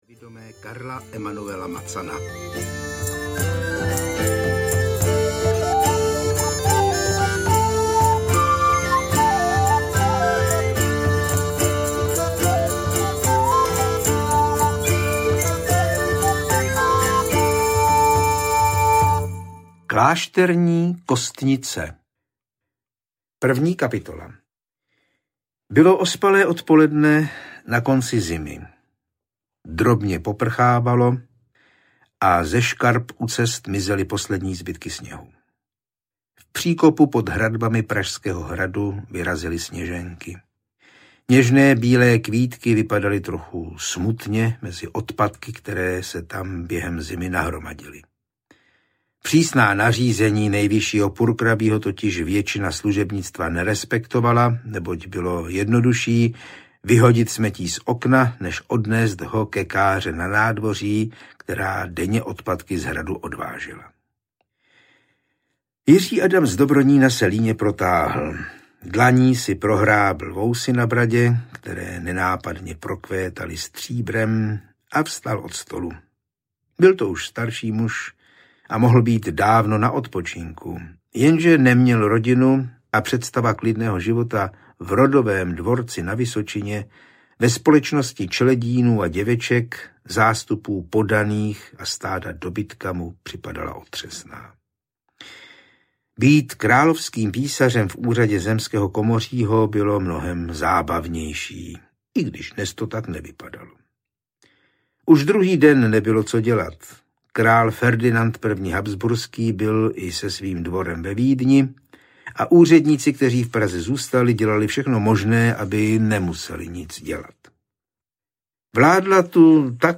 Klášterní kostnice audiokniha
Ukázka z knihy